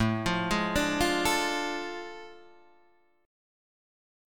A Major 7th Suspended 4th Sharp 5th